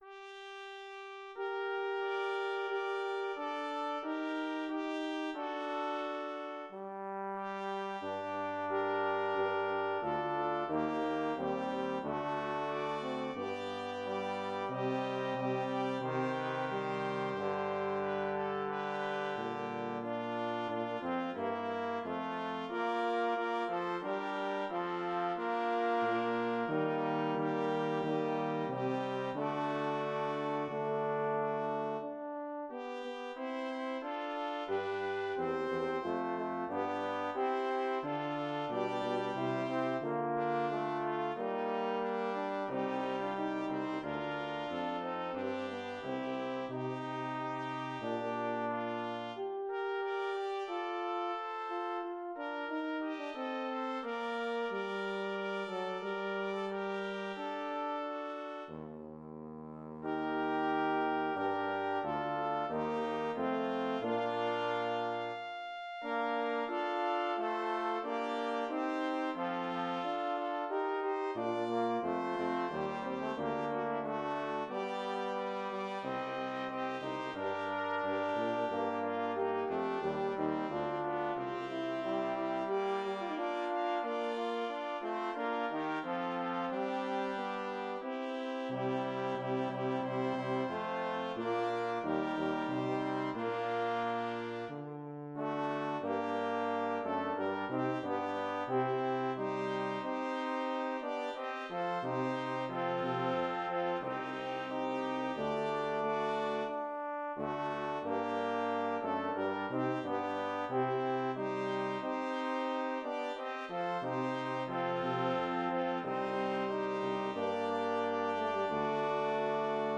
Synthesized Performance *#623447
Performers MIDI Copyright Creative Commons Attribution-ShareAlike 4.0 [ tag / del ] Misc.